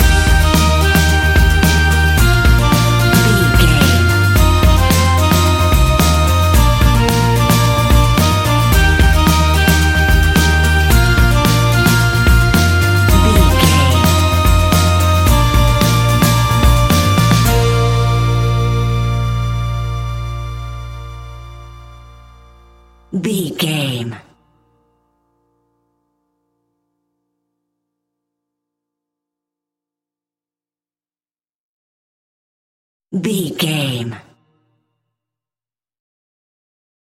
Uplifting
Ionian/Major
E♭
acoustic guitar
mandolin
double bass
accordion